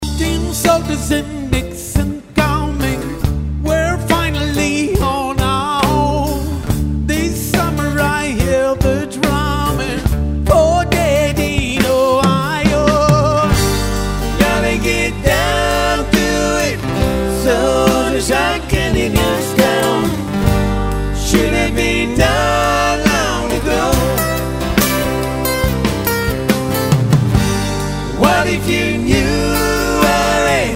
privilégiant les instruments acoustiques.